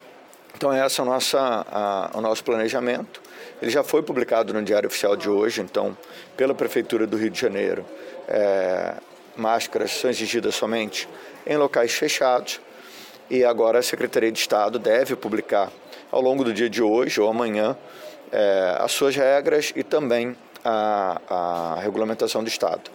O Secretário municipal de saúde, Daniel Soranz, em entrevista no Centro de Operações, disse acreditar que o governo do Estado publique ainda hoje, ou, no máximo, nesta quinta-feira, o decreto liberando o uso de máscaras em espaços abertos.